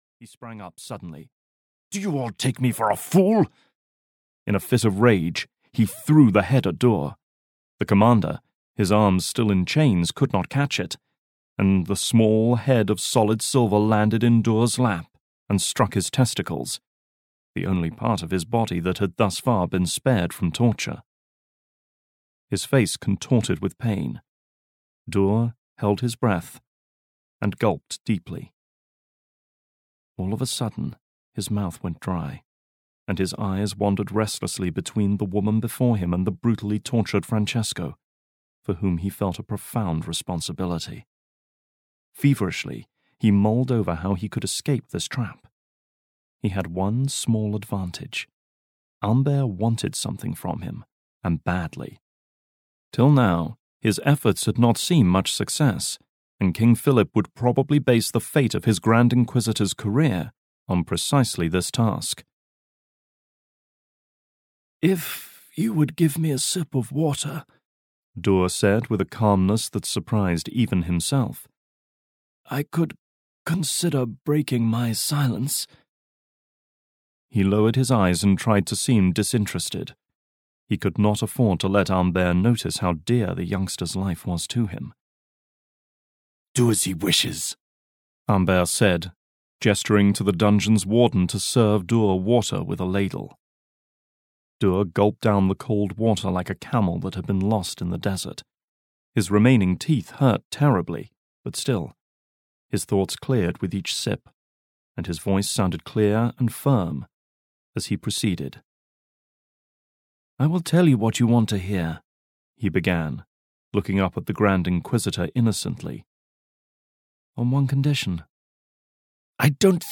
Mystery of the Templars (EN) audiokniha
Ukázka z knihy